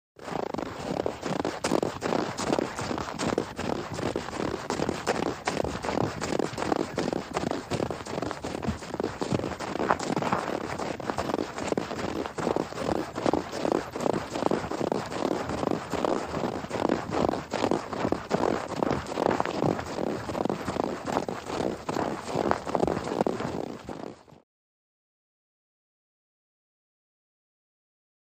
Jog In Snow | Sneak On The Lot
Jogging In Snow, Crunchy Footsteps.